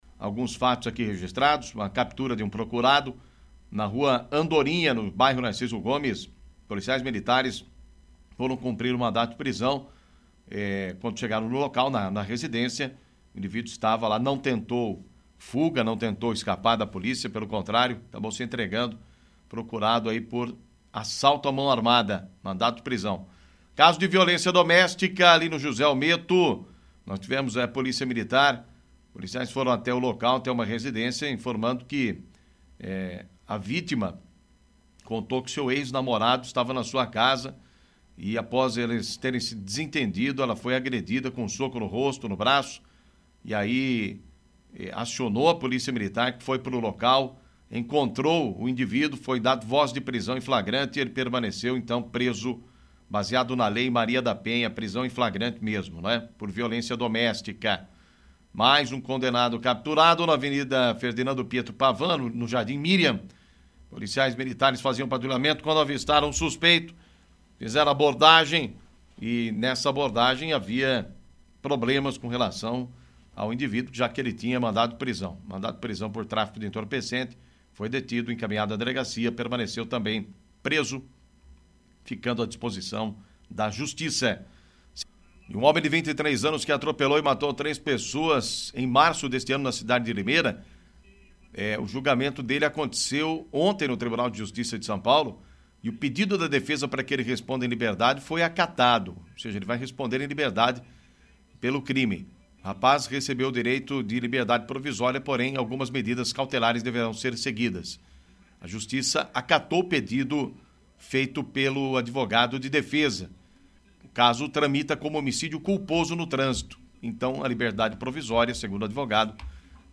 Clique no link abaixo e ouça as principais notícias ocorridas em Araras e região na voz do repórter policial